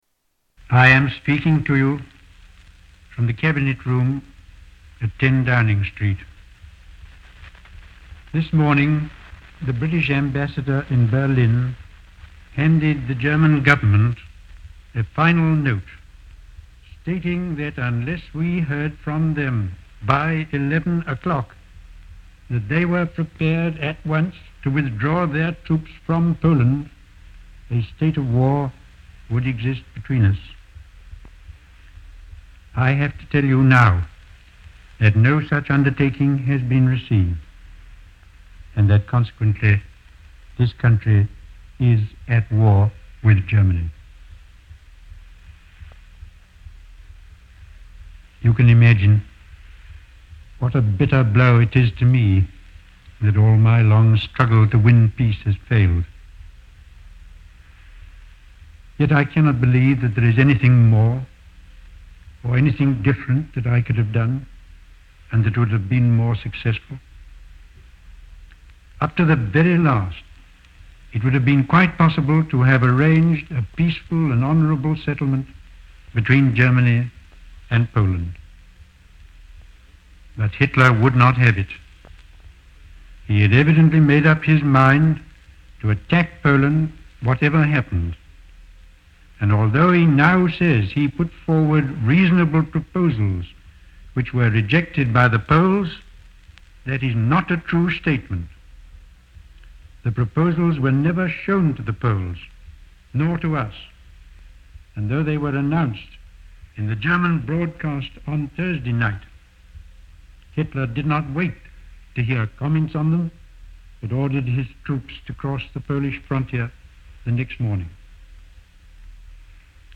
Tags: Historical Neville Chamberlain Audio Neville Chamberlain Speeches Arthur Neville Chamberlain Neville Chamberlain Sounds